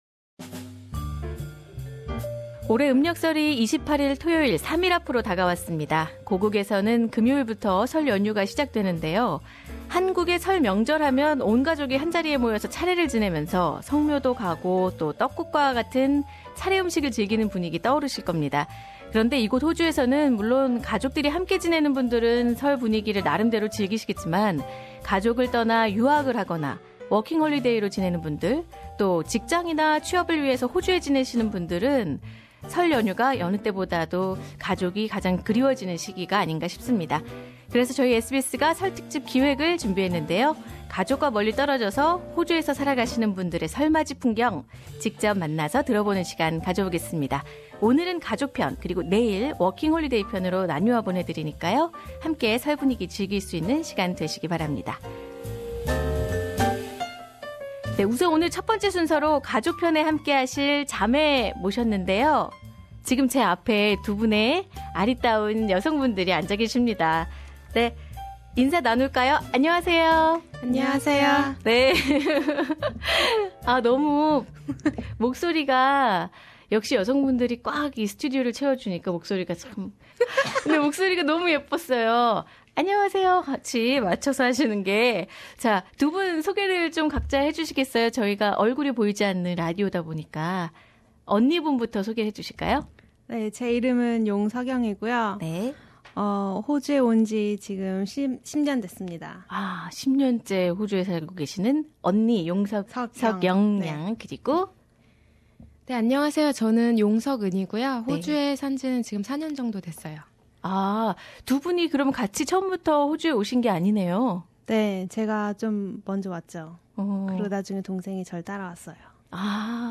[설특집 인터뷰] 호주에서 맞는 설맞이 풍경 1부